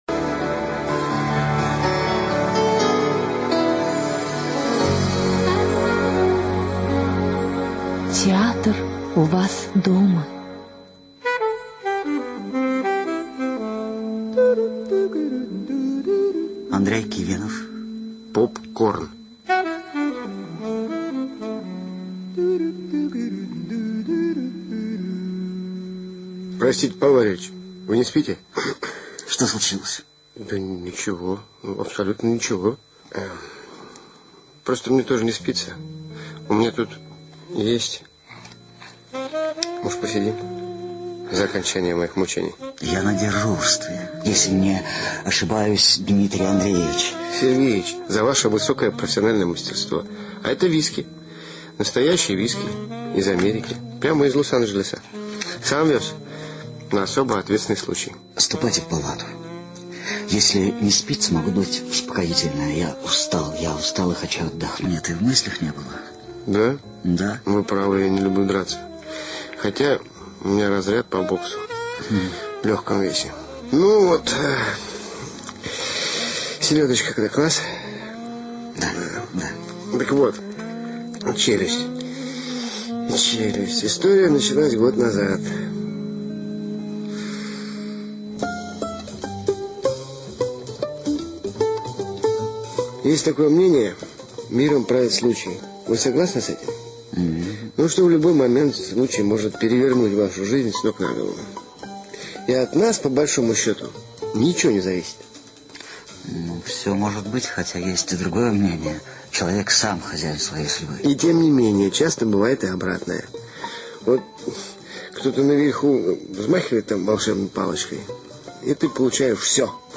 ЧитаютЛыков А., Бехтерев С.
ЖанрРадиоспектакли